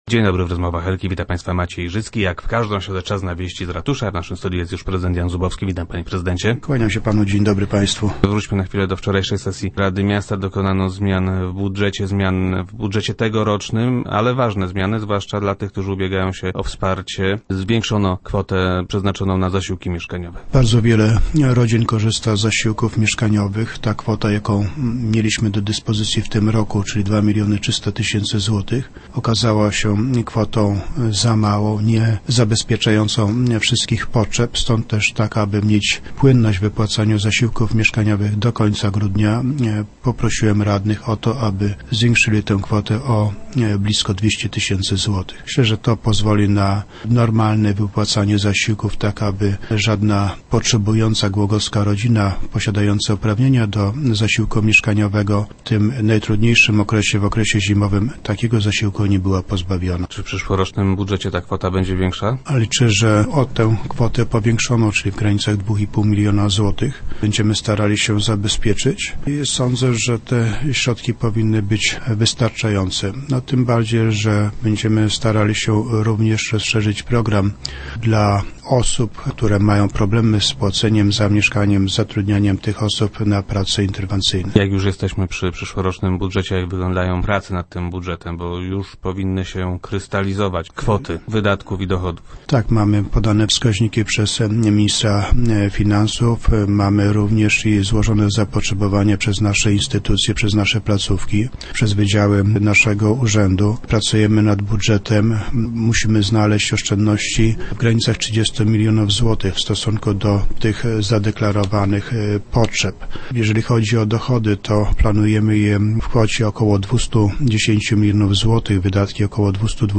- Zaczęło nam tych pieniędzy w budżecie już brakować - poinformował prezydent Jan Zubowski, który był gościem Rozmów Elki.